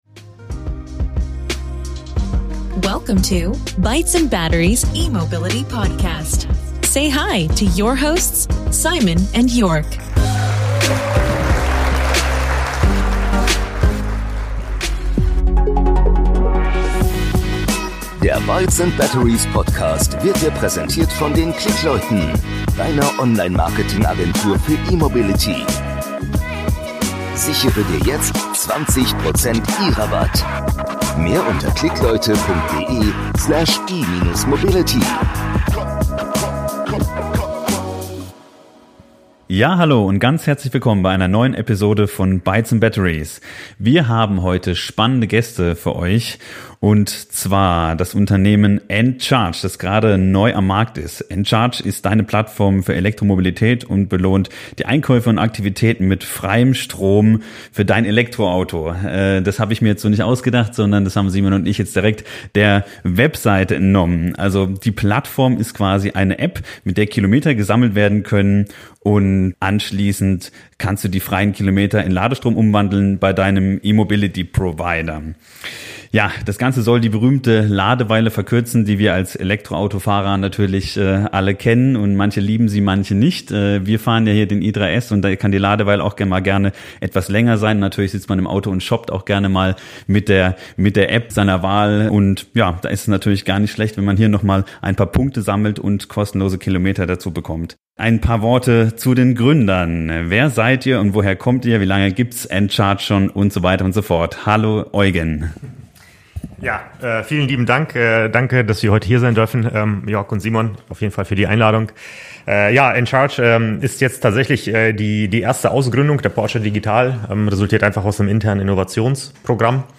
Übrigens: Das Unternehmen ist die erste Ausgründung aus dem Hause Porsche Digital und die Macher hinter der Applikation, welche euch heute in dieser Podcast-Episode Rede und Antwort stehen, waren bereits selbst beim Aufbau von Lade-Infrastruktur beteiligt.